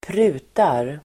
Uttal: [²pr'u:tar]